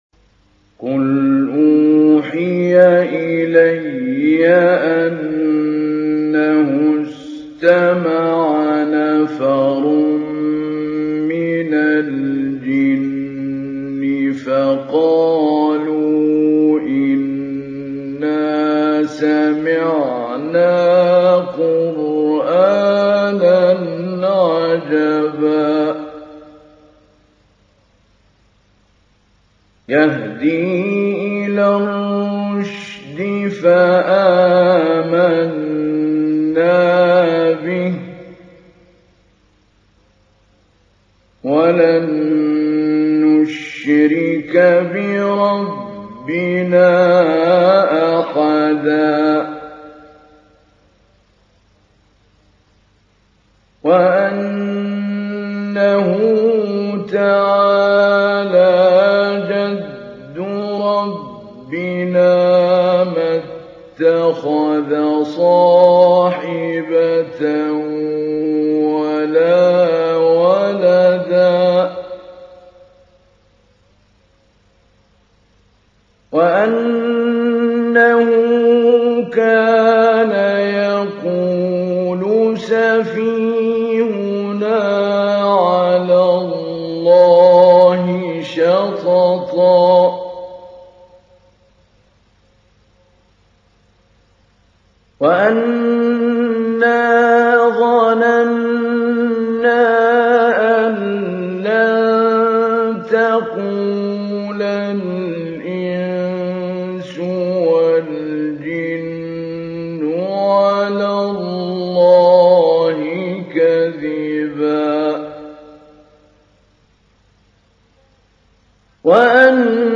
تحميل : 72. سورة الجن / القارئ محمود علي البنا / القرآن الكريم / موقع يا حسين
موقع يا حسين : القرآن الكريم 72.